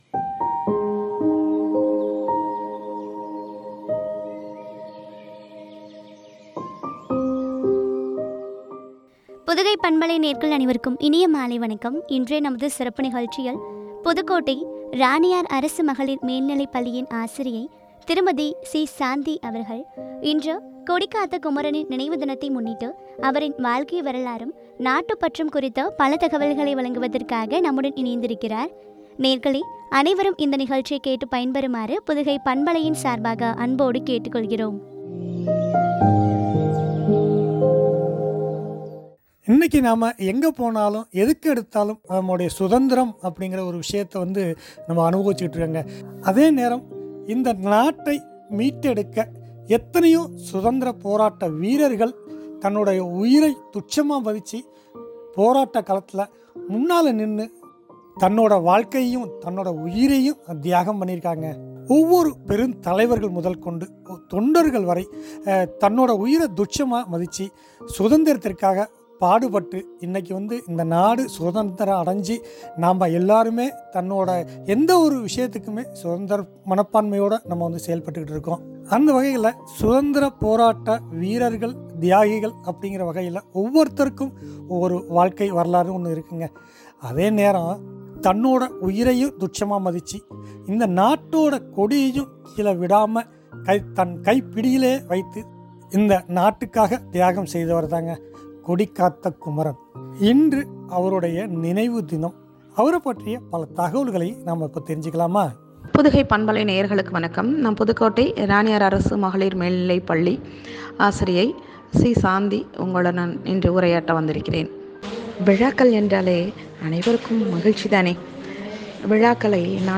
கொடிகாத்த குமரனின் வாழ்க்கை வரலாறும், நாட்டுப்பற்றும் பற்றிய உரையாடல்.